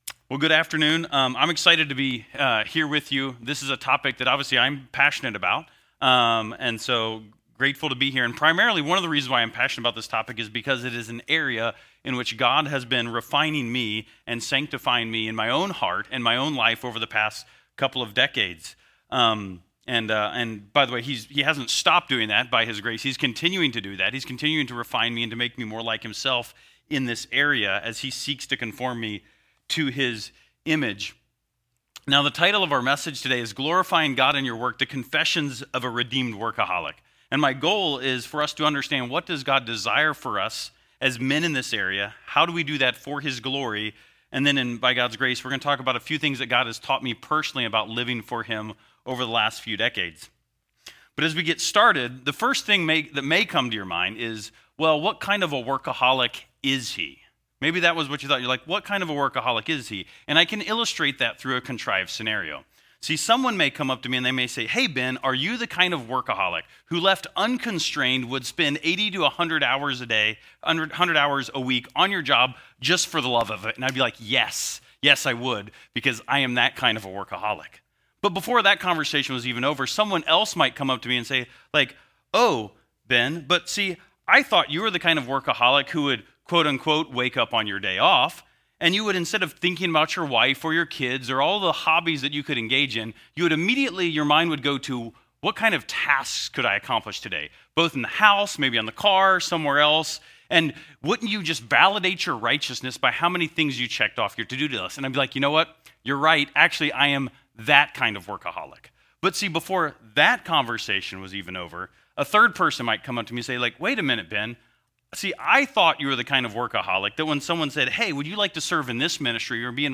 Beginnings - Fall Retreat 2024 - A Biblical Everyday Life